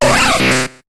Cri de Kabutops dans Pokémon HOME.